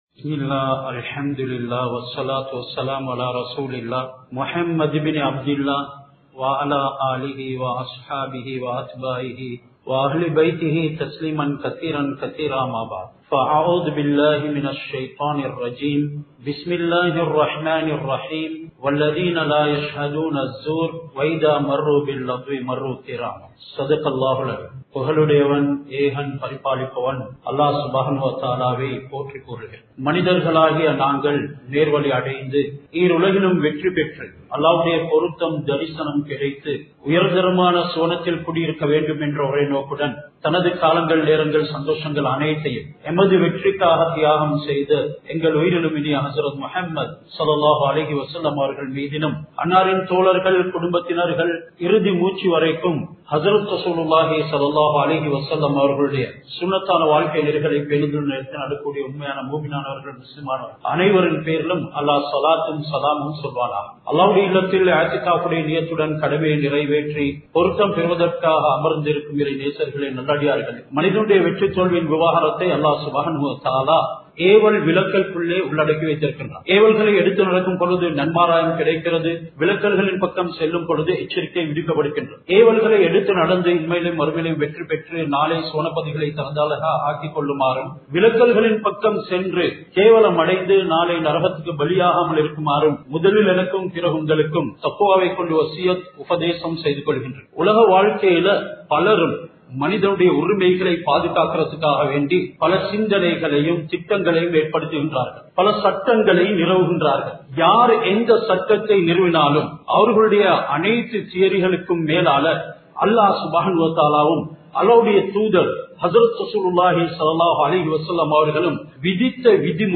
பொய் சாட்சியின் விளைவு | Audio Bayans | All Ceylon Muslim Youth Community | Addalaichenai
Jawatha Jumua Masjith